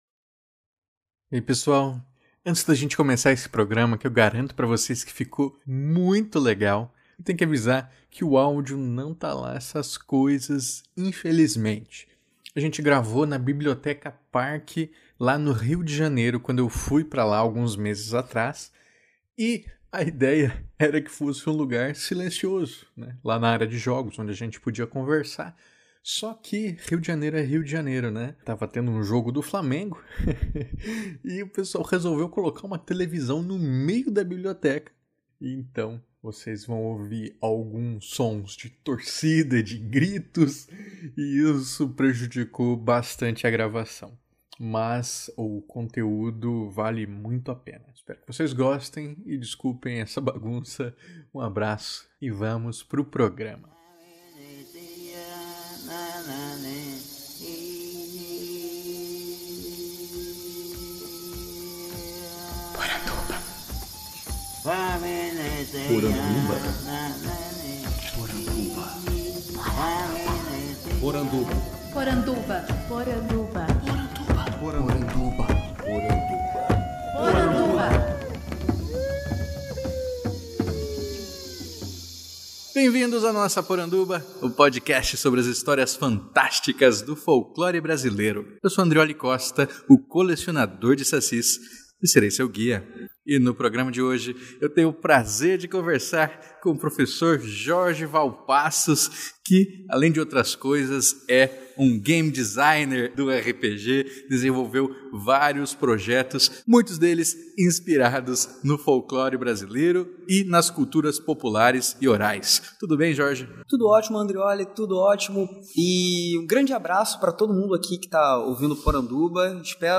– Canto de abertura e encerramento do povo Ashaninka .